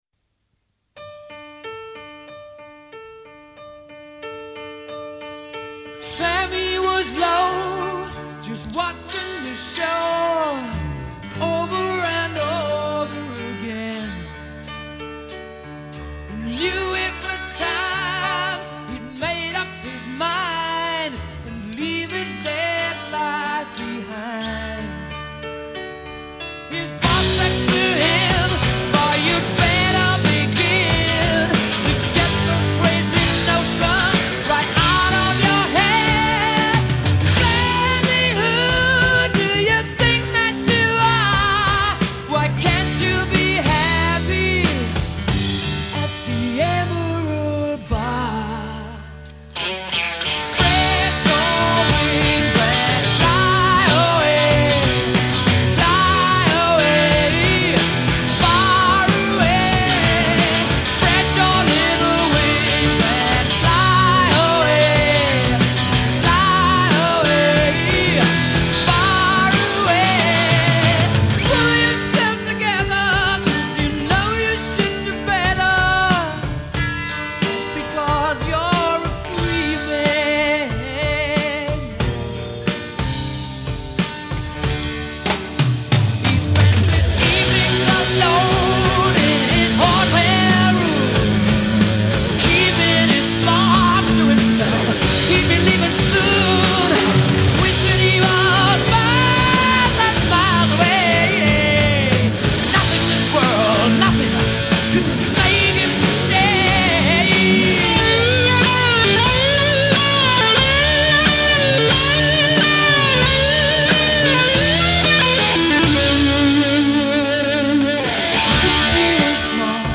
rare live-in-studio performance